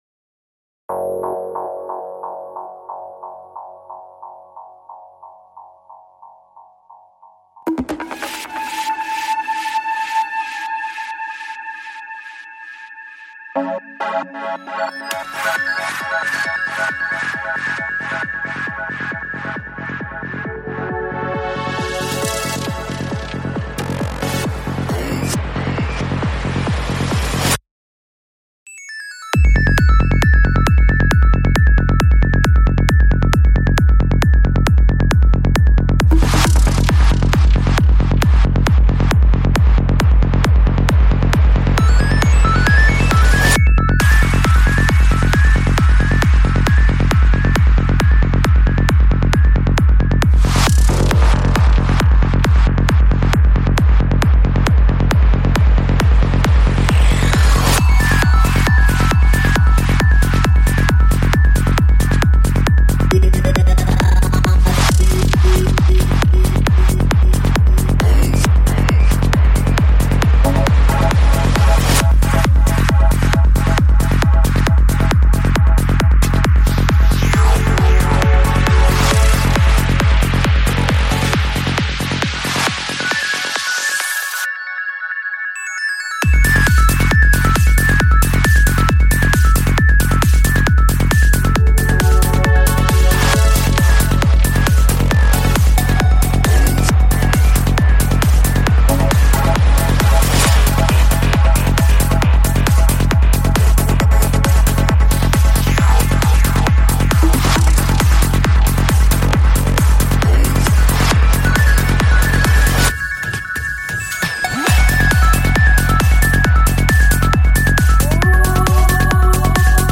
Psy-Trance